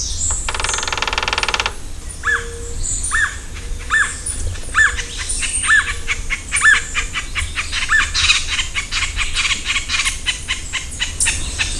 Puerto Rican Woodpecker
Melanerpes portoricensis
VOZ: Los llamados incluyen un serie ruidosa de notas "uica". Tamborea en los árboles, pero no tan frecuentemente como otros carpinteros en América del Norte.